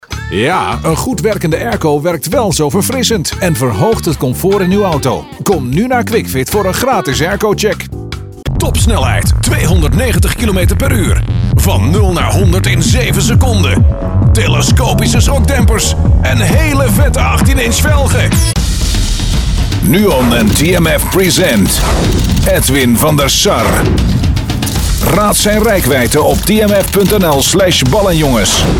Stimme mit eigener studio und ISDN-APT.
Sprechprobe: Werbung (Muttersprache):
Excellent voice-over for trailers/commercials/films & impersonations in Dutch and English.